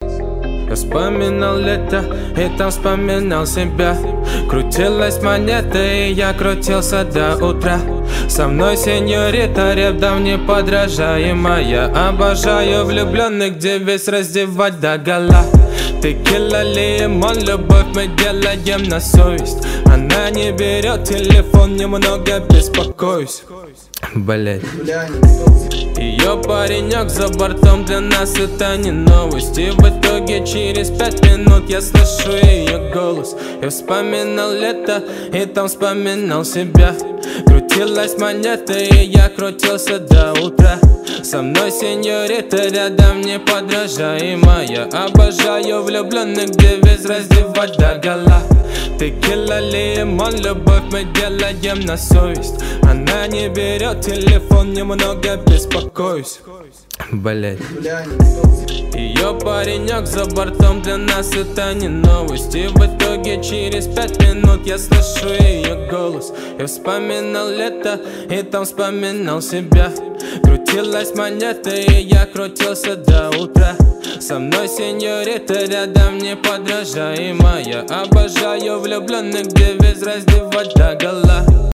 • Качество: 256, Stereo
русский рэп
качающие